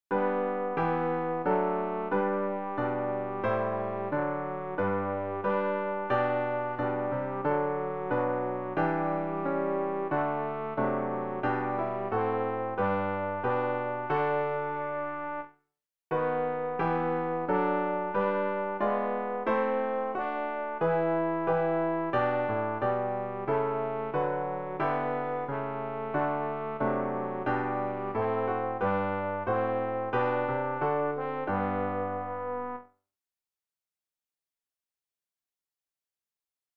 rg-594-nun-ruhen-alle-waelder-tenor.mp3